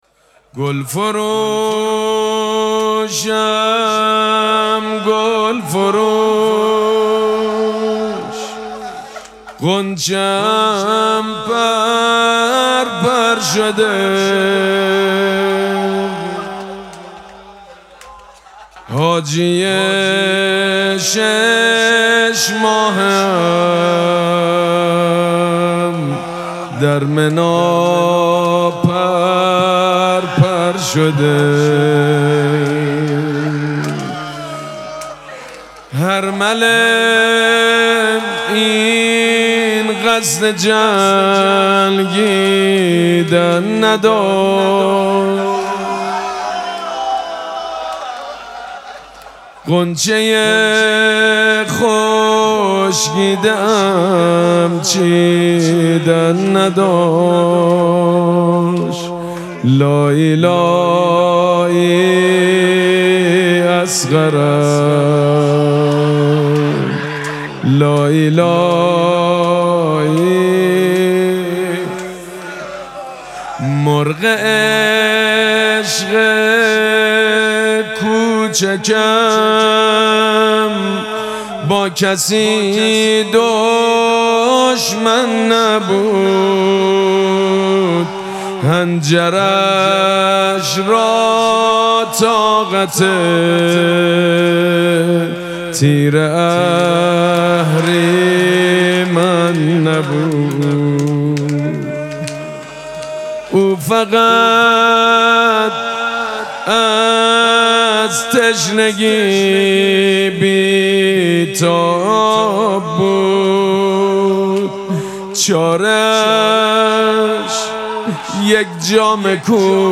مراسم مناجات شب هفتم ماه مبارک رمضان
مناجات
حاج سید مجید بنی فاطمه